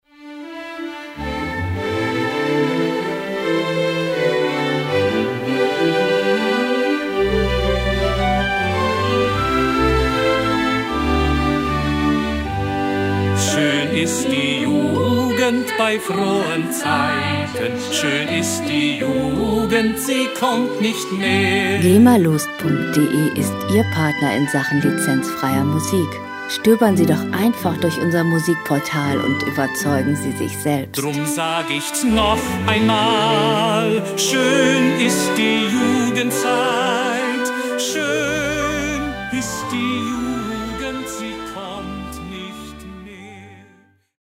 Musikstil: Volkslied
Tempo: 82 bpm
Tonart: G-Dur
Charakter: heimisch, traditionell
Instrumentierung: Männergesang, Chor, Orchester, Harfe